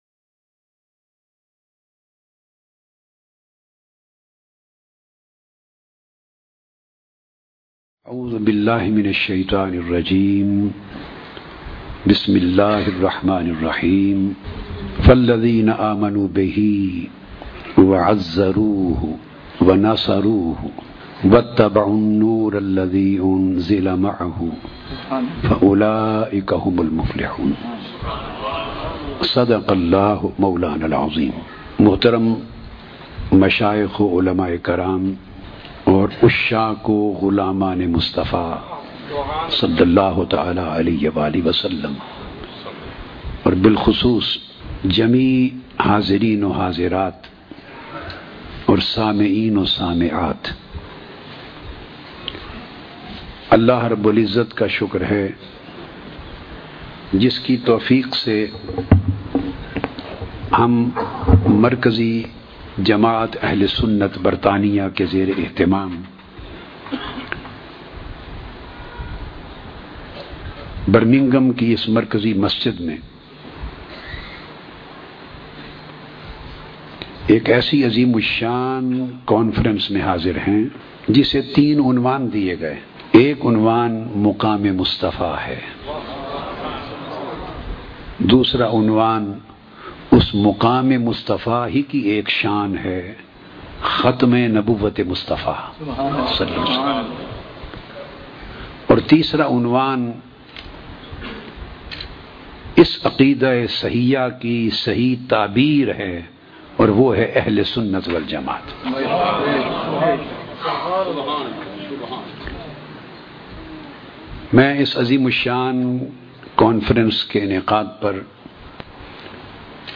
Dr tahir ul qadri mp3 speech download maqam e mustafa aur aqeeda e ahle sunat